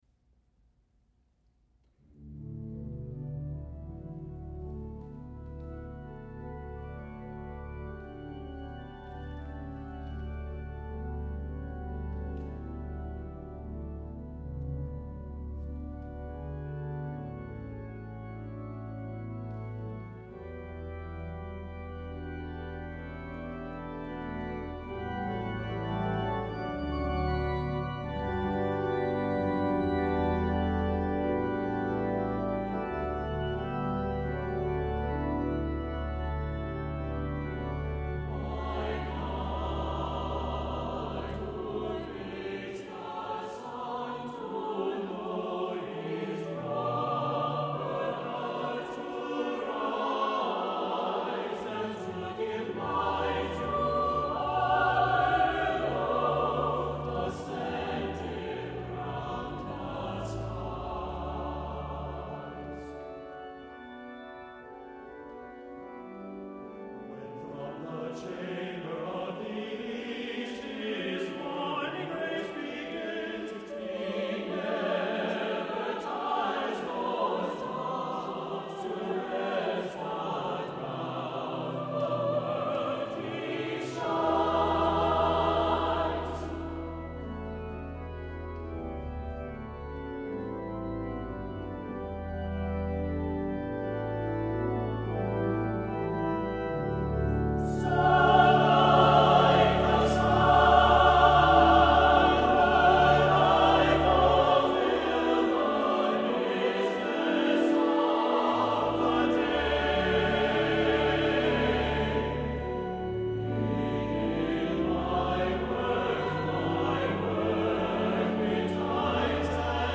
• Music Type: Choral
• Voicing: SATB
• Accompaniment: Organ
• richly harmonized and oftentimes hymn-like in simplicity
• organ part requires big hands and good legato style